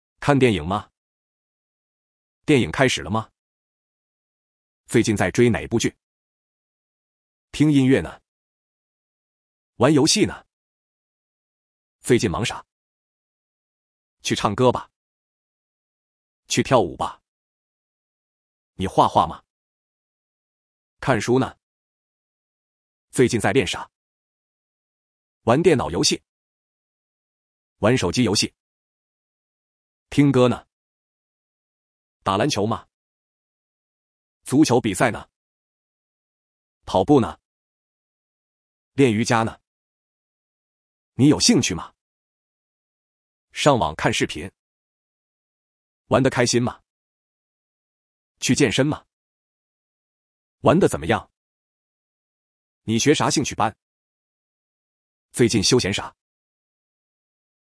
Fast Spoken Mandarin (native speech)